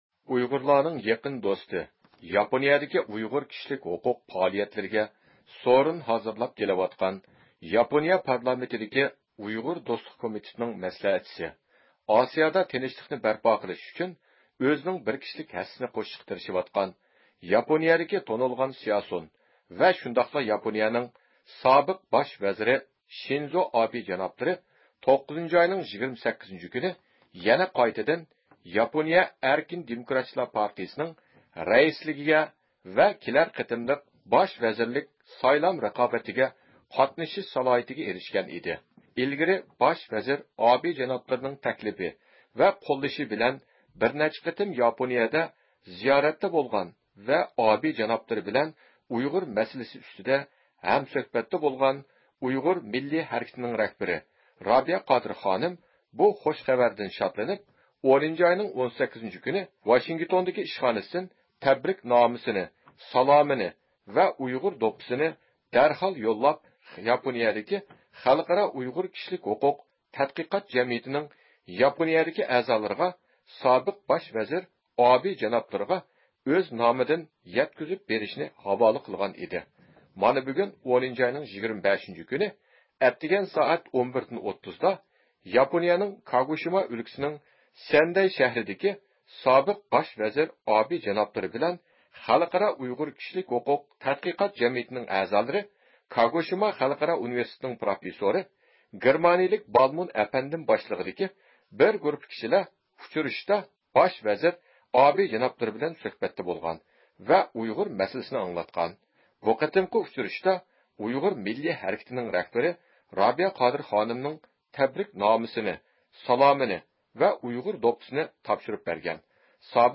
بىز بۇ قېتىمقى ئۇچرىشىشتا سابىق باش ۋەزىر ئابې جانابلىرىنىڭ ئۇيغۇر مىللىي ھەرىكىتىنىڭ رەھبىرى رابىيە قادىر خانىمغا يەتكۈزگەن سالىمىنى يوللاش ۋە رابىيە قادىر خانىمنىڭ تەبرىكنامىسىنىڭ مەزمۇنىدىن ۋاقىپ بولۇش ئۈچۈن رابىيە قادىر خانىم بىلەن تېلېفون سۆھبىتى ئېلىپ باردۇق.